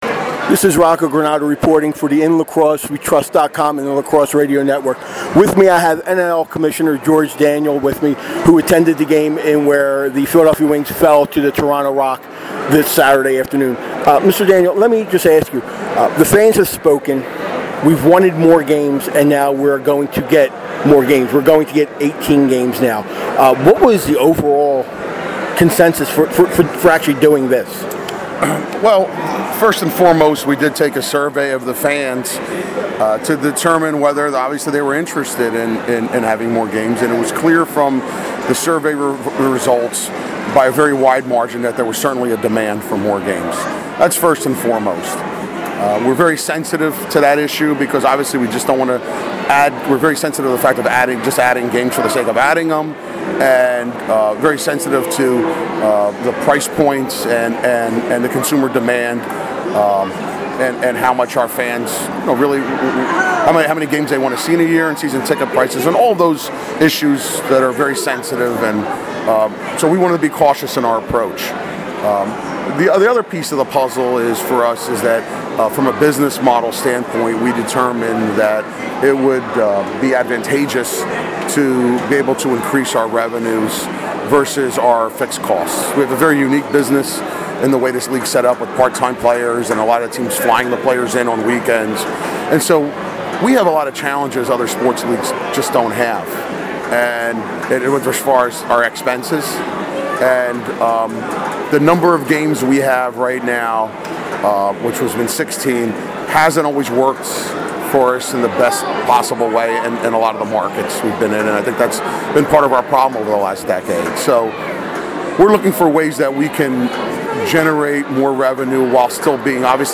at the Toronto Rock vs Philadelphia Wings contest yesterday